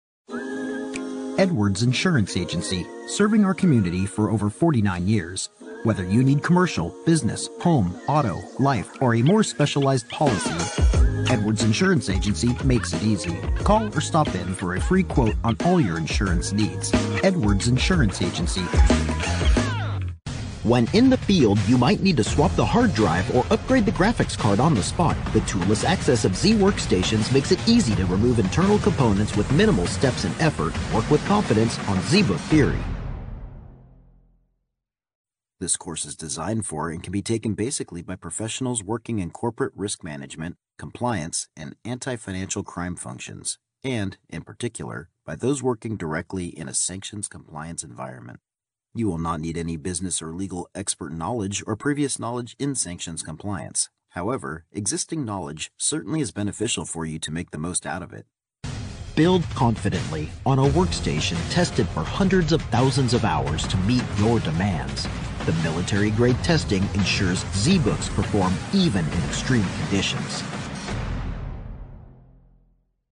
英语样音试听下载